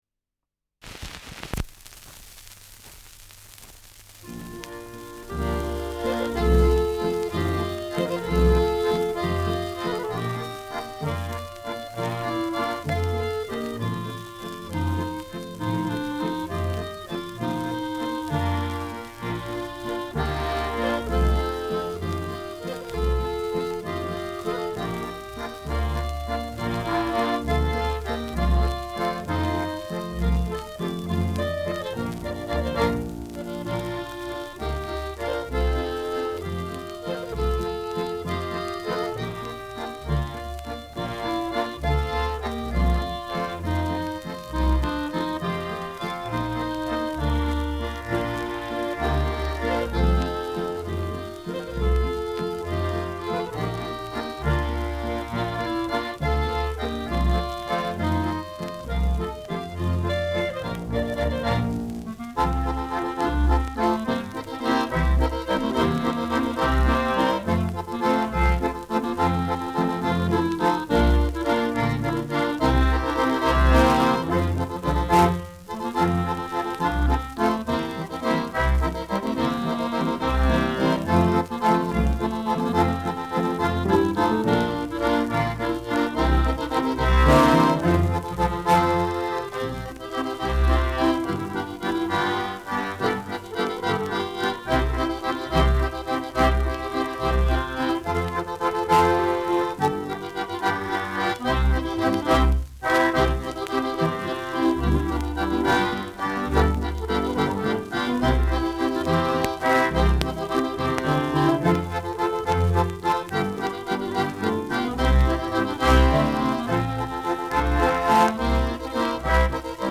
Walzer
Schellackplatte
Ländlerkapelle*,FVS-00018